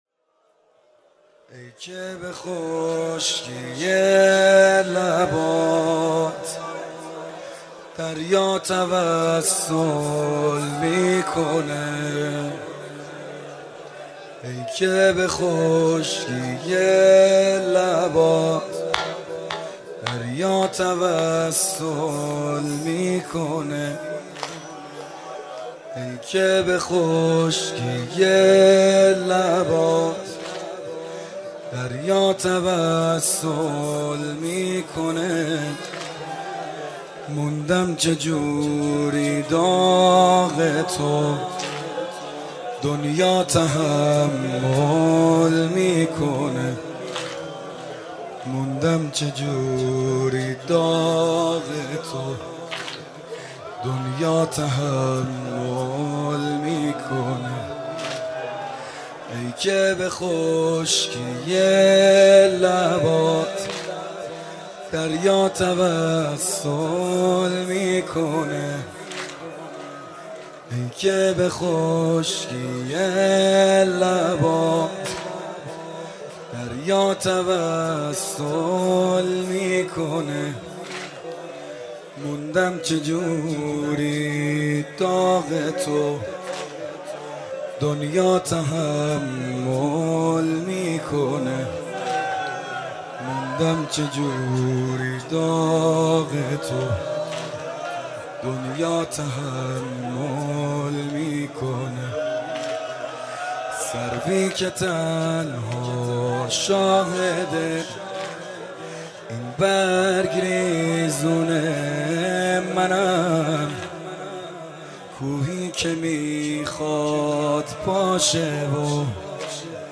صوت/ مداحی سعید حدادیان در روز عاشورا
صوت مداحی حاج سعید حدادیان در روز عاشورا در هیات محبان فاطمه زهرا سلام الله علیها منتشر می شود.